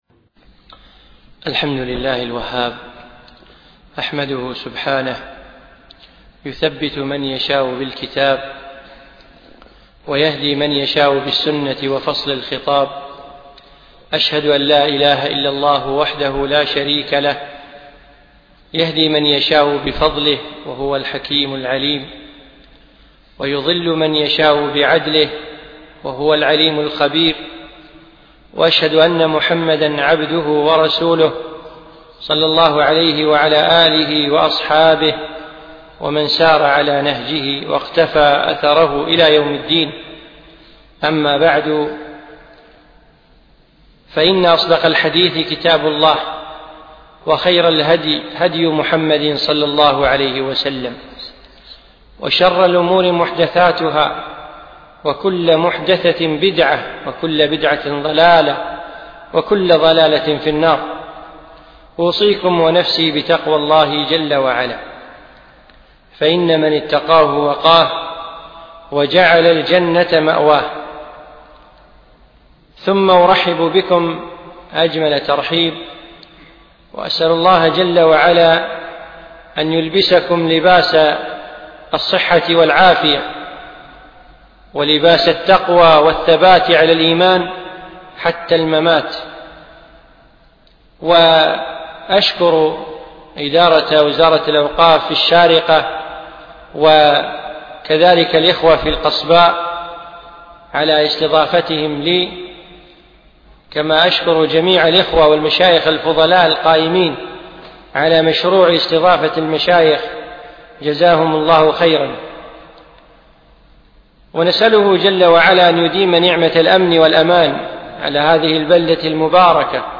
أقيمت هذه المحاضرة في دولة الإمارات